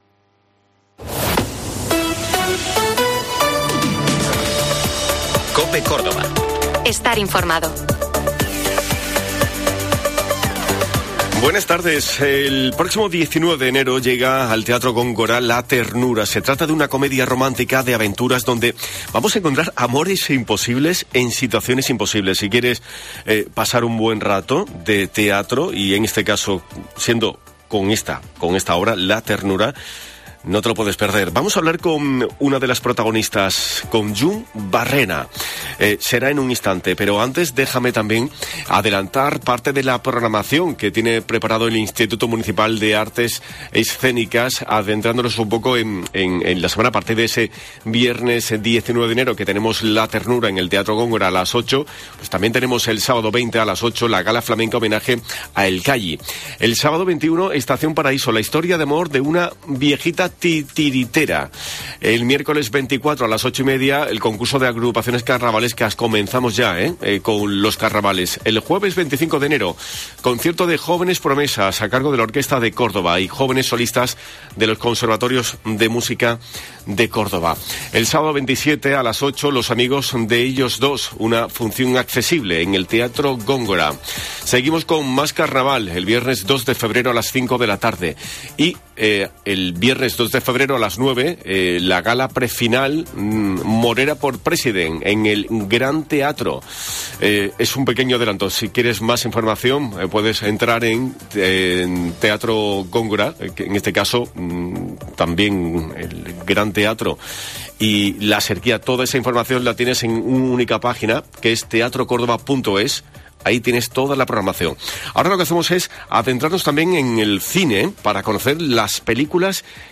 Hemos hablado con una de sus protagonista, la actiz Llum Barrera.